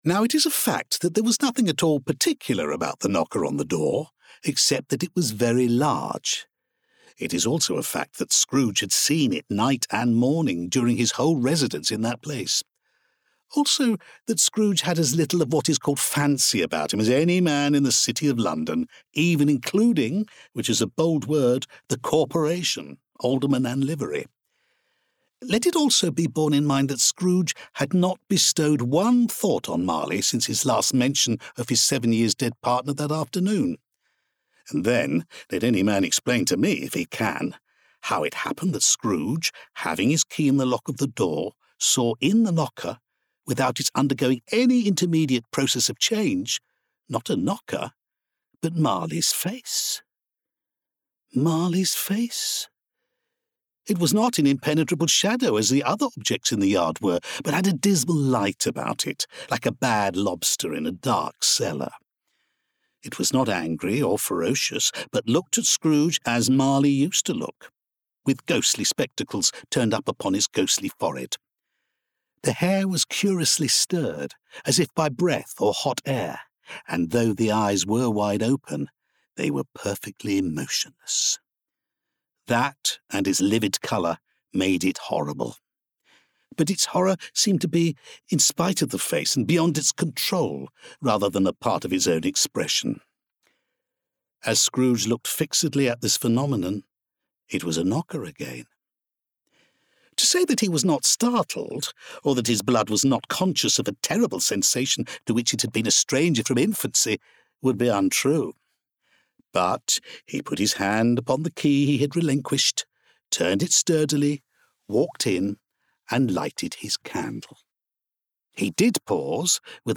Native Accent: RP Characteristics: Distinct and Engaging Age
Audiobook